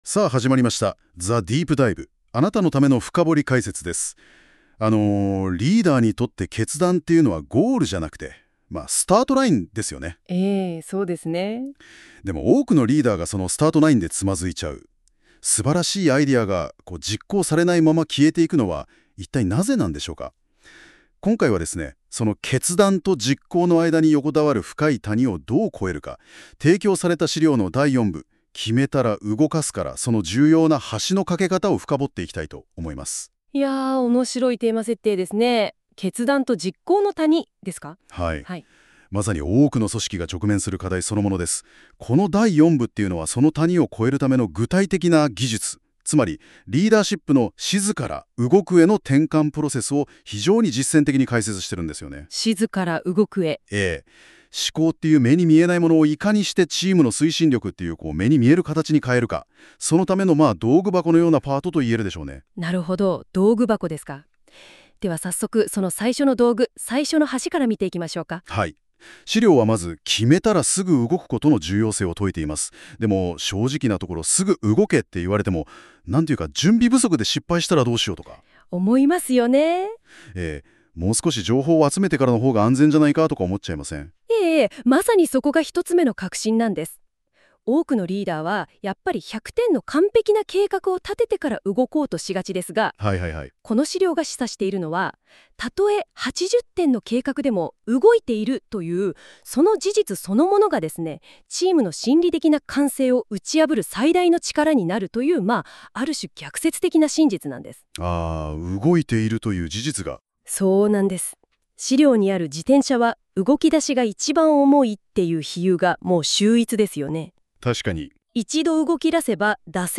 第四部の内容を、AI（NotebookLM）がPodcast風の対談形式で解説してくれました。
▶ 音声で聴く：実行のリズム「静と動」の深掘り解説 ※この音声はAIによる自動生成です。